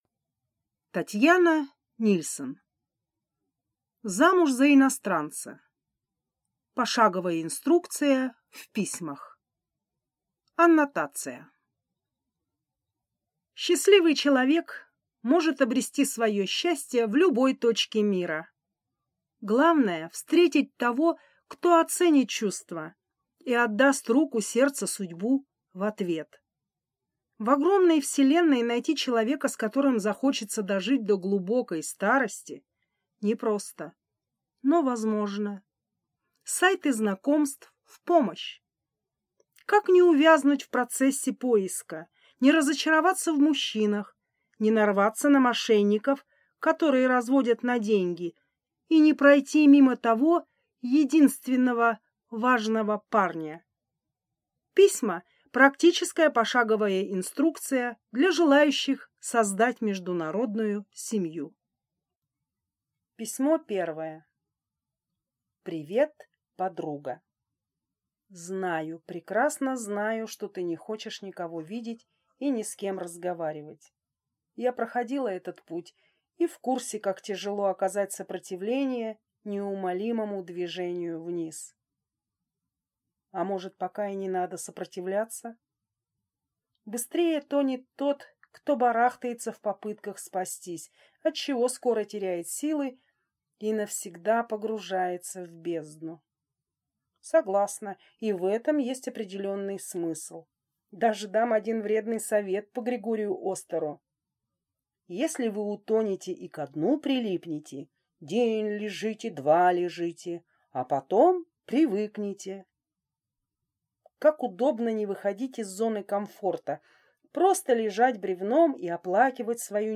Аудиокнига Замуж за иностранца – пошаговая инструкция в письмах | Библиотека аудиокниг